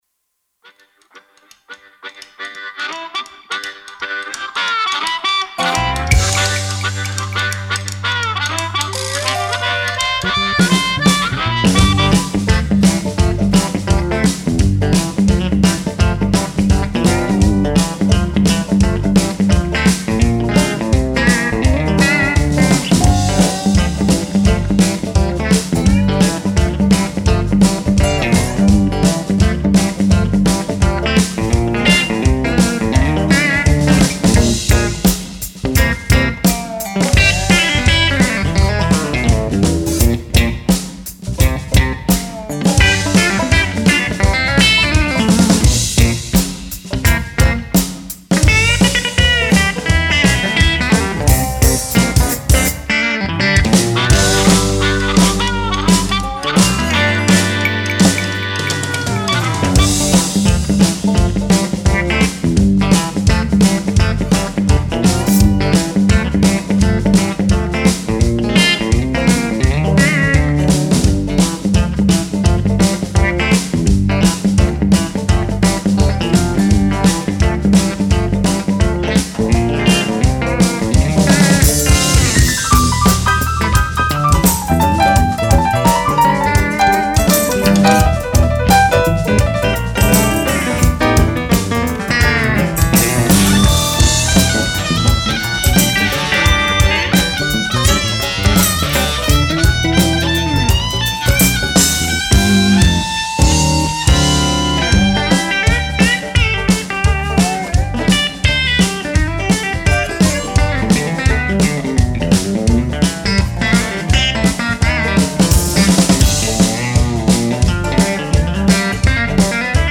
I wanted to compose a swampy Cajun tune to match the vibe.
bass
Drums
blues harp